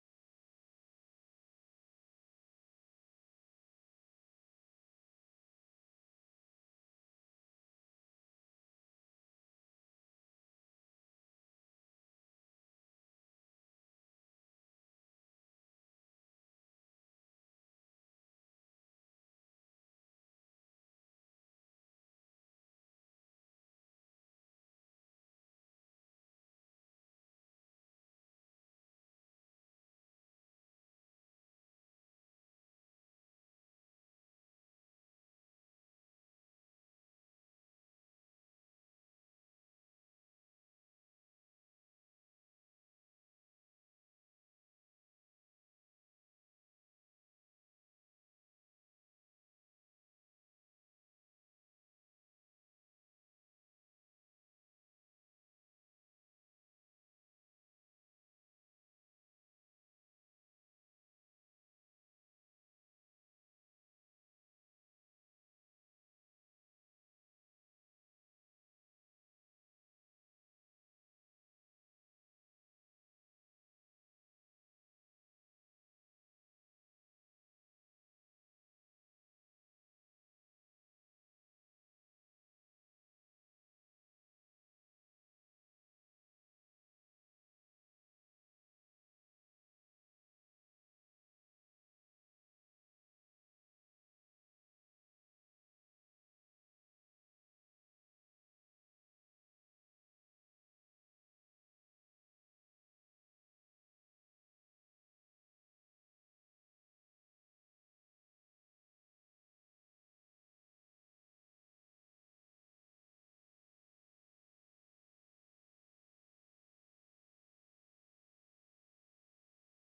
In the beginning we work through a small audio issue, it is cleared up in a few minutes.